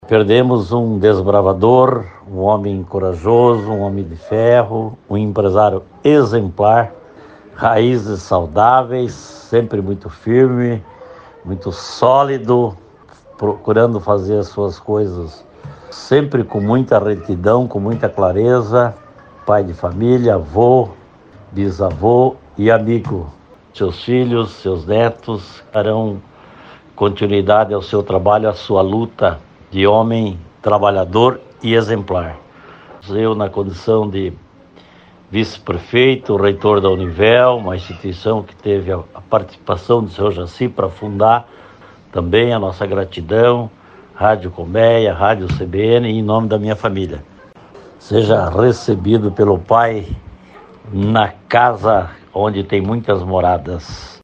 Player Ouça RENATO SILVA, VICE-PREFEITO DE CASCAVEL